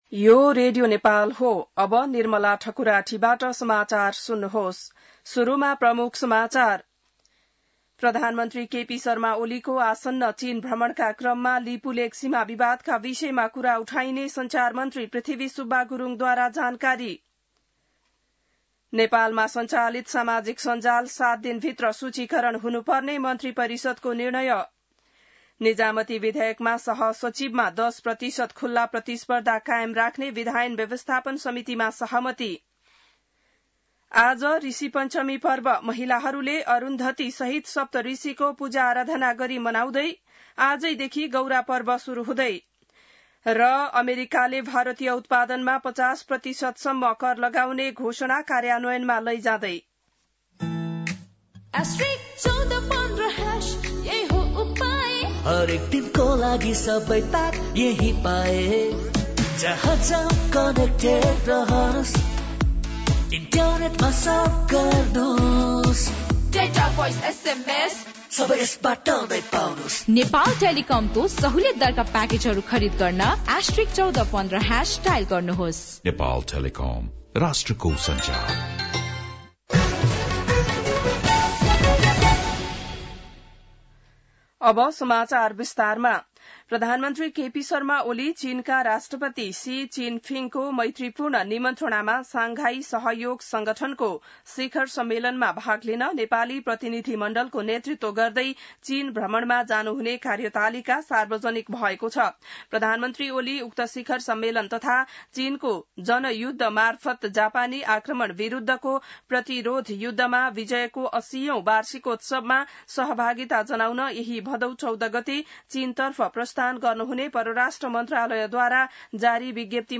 बिहान ७ बजेको नेपाली समाचार : १२ भदौ , २०८२